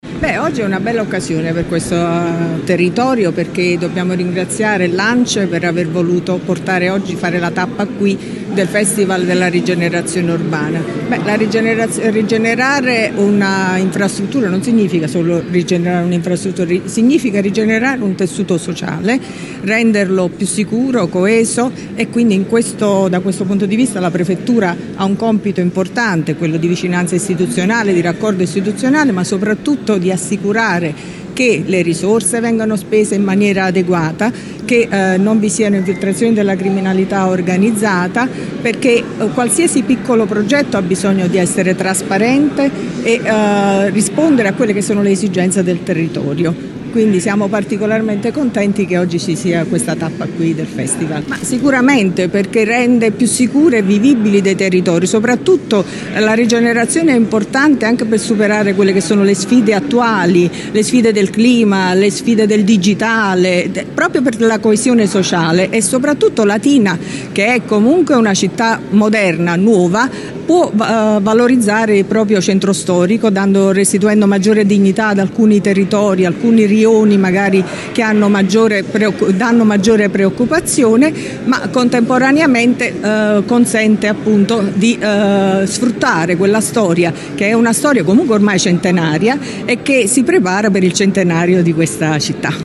La tappa di Latina che andrà avanti per tutta la giornata è iniziata alle 10 al Museo Cambellotti.
e il prefetto di Latina Vittoria Ciaramella.